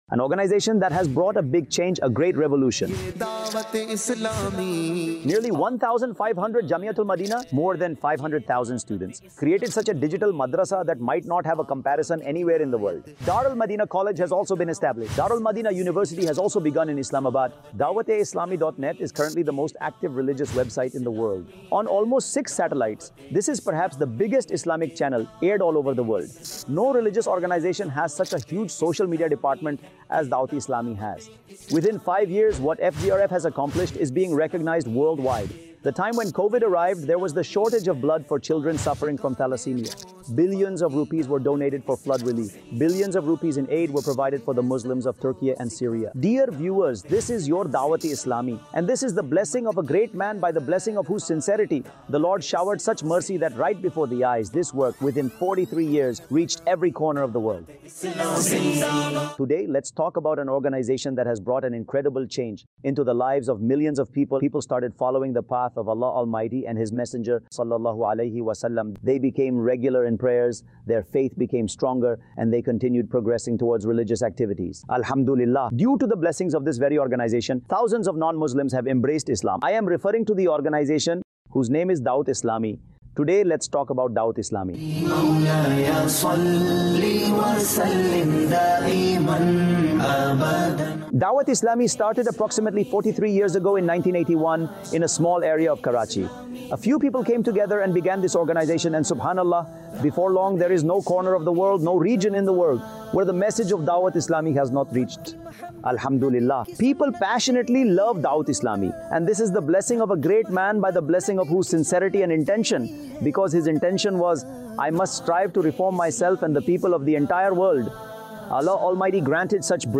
Introduction To Dawateislami | Documentary 2025 | 11 Minutes Documentary | AI Generated Audio Mar 11, 2025 MP3 MP4 MP3 Share دعوت اسلامی کا تعارف | ڈاکیومینٹری 2025 | 11 منٹس کی ڈاکیومینٹری | اے آئی جنریٹڈ آڈیو